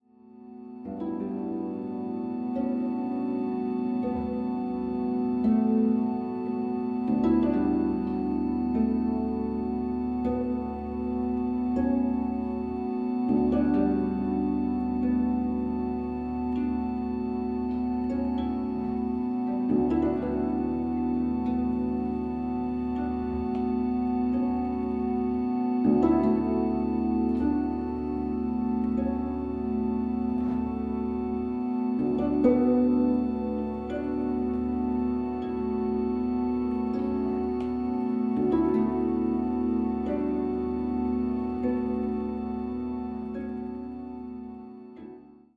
十七絃箏と、カリンバ／ハーモニウム／女声による音の綴り。
(17-strings koto)
voice, harmonium